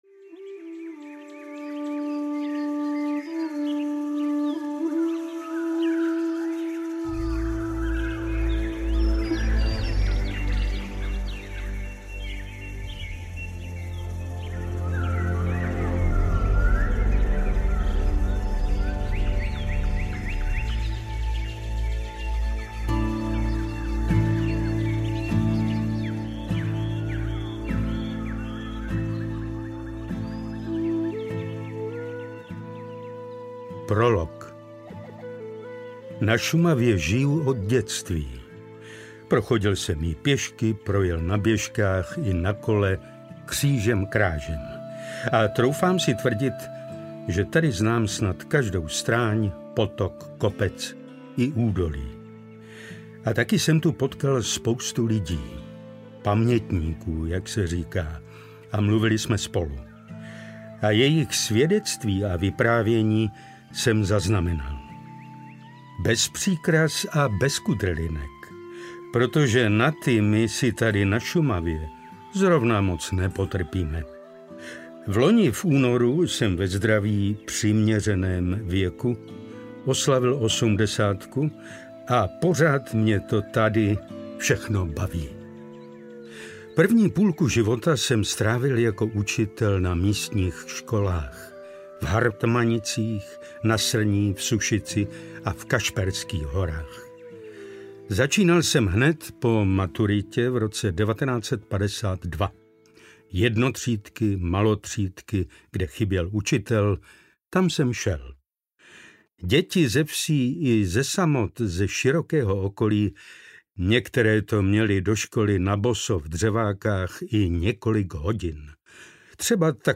Zmizelá Šumava audiokniha
Ukázka z knihy
• InterpretPetr Štěpánek